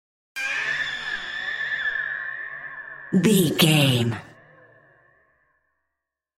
High Witch Voices.
Sound Effects
Atonal
ominous
eerie